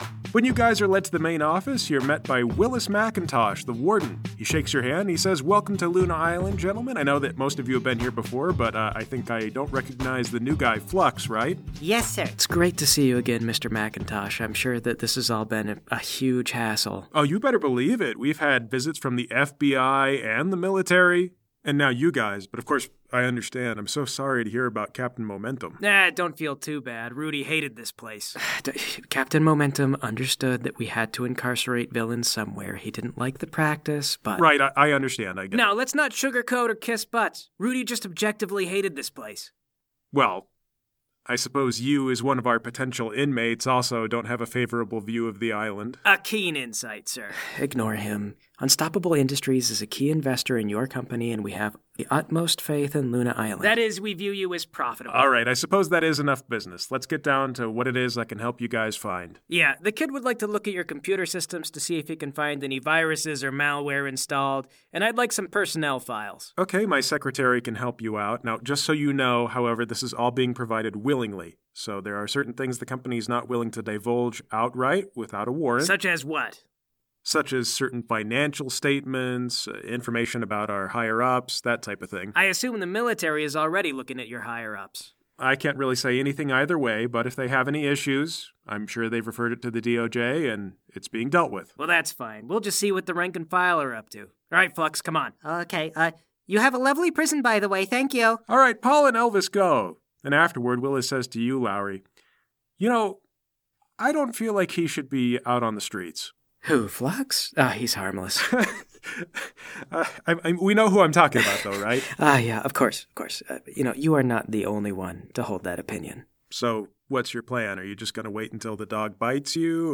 … continue reading 116 epizódok # Lifestyle # Hobbies # Comedy # Audio Drama # Dawn